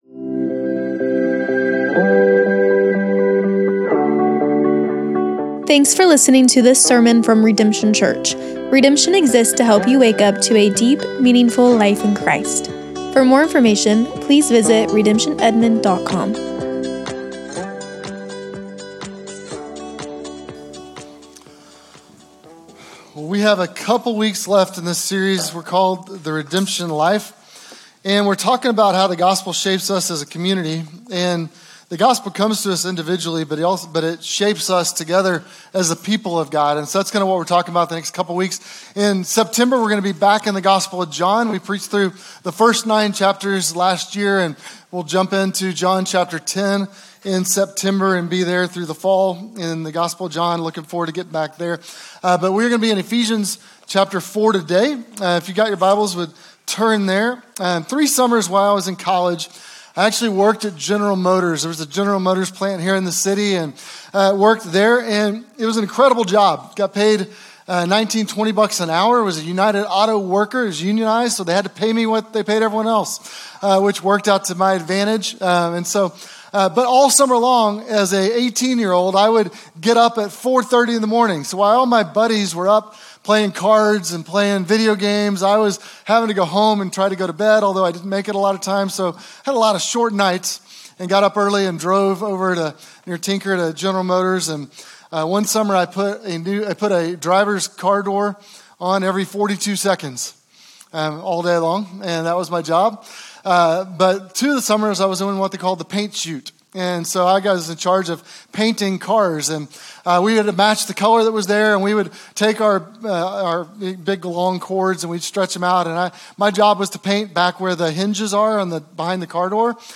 SERMONS - Redemption Church